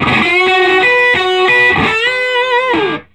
Index of /90_sSampleCDs/USB Soundscan vol.22 - Vintage Blues Guitar [AKAI] 1CD/Partition C/13-SOLO B060